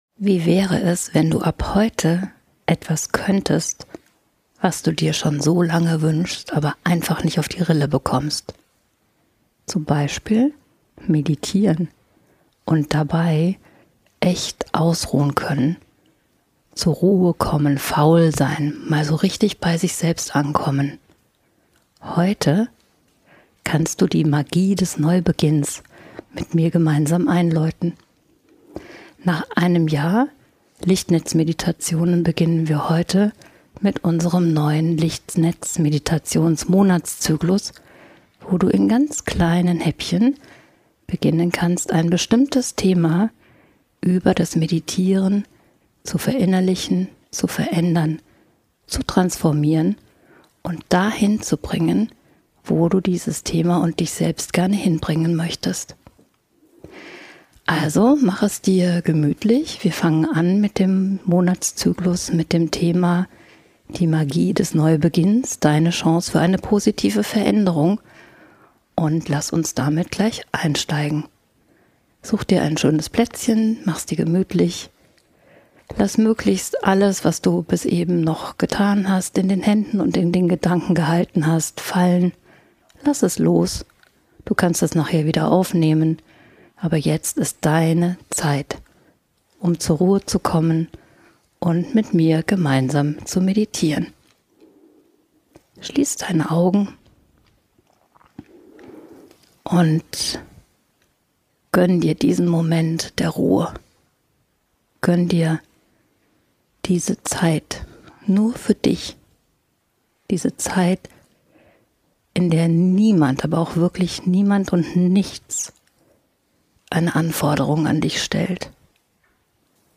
Im Mittelpunkt steht das Thema: Anleitung für einen Neubeginn – auch beim Meditieren Was möchtest du neu beginnen? Was darf hinter dir bleiben? Was willst du in dein Leben einladen? In dieser Folge teile ich mit dir Gedanken, Erfahrungen – und eine transformierende Meditation zum Thema Neubeginn, auch was das Meditieren selbst anbelangt.